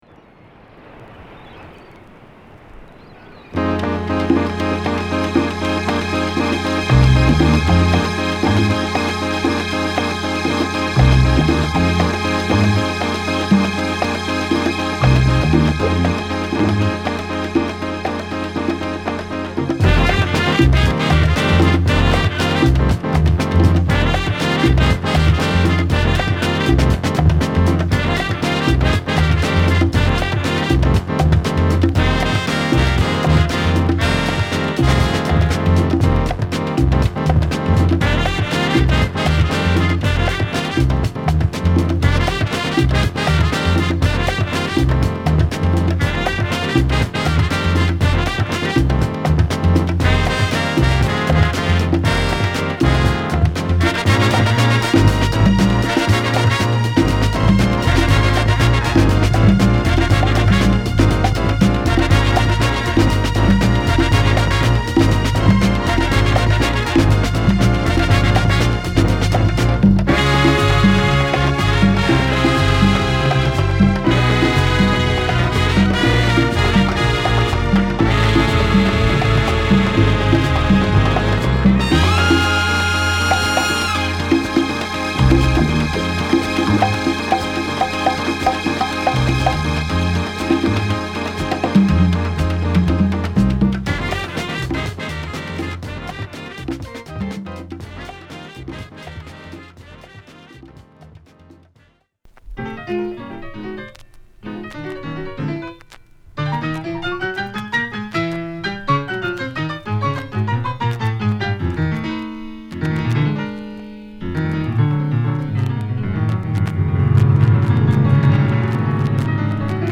＊B1見た目は綺麗ですが、ジリっとノイズが入ります。